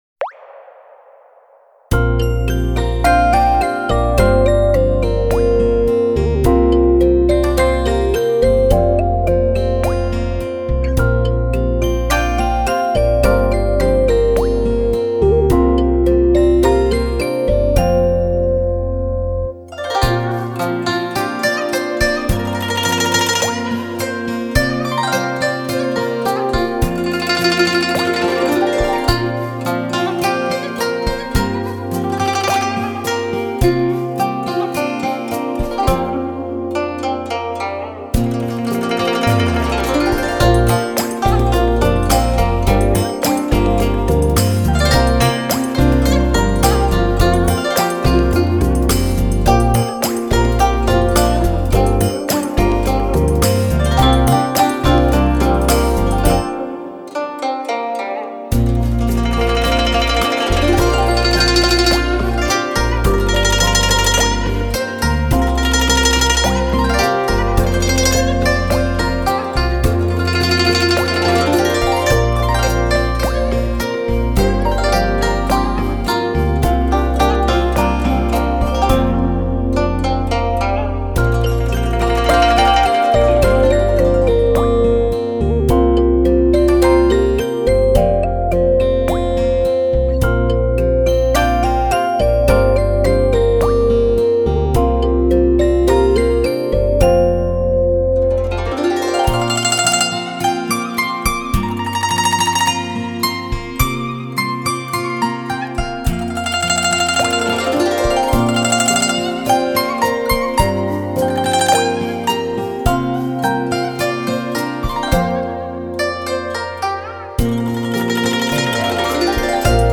时而梦幻，时而写实，曲风可清新明朗，也可激昂的狂烈，不管什么风格，都具有很令人惊艳的旋律性。